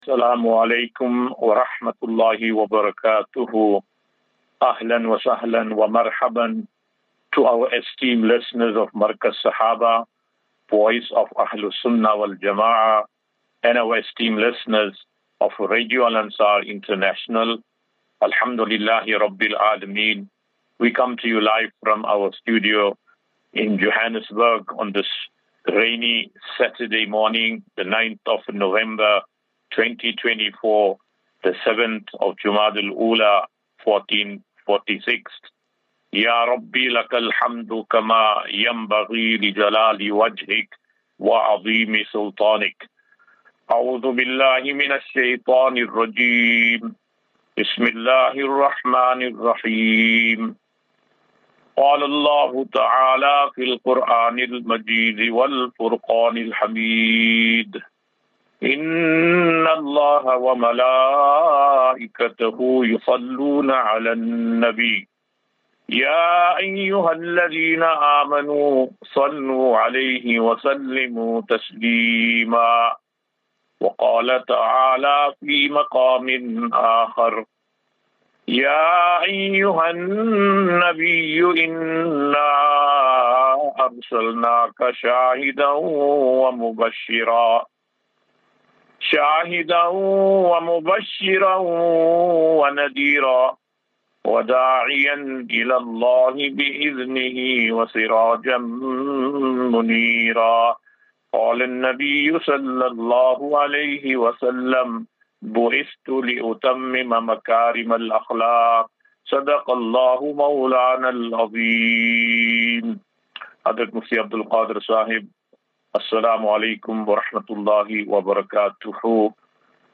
QnA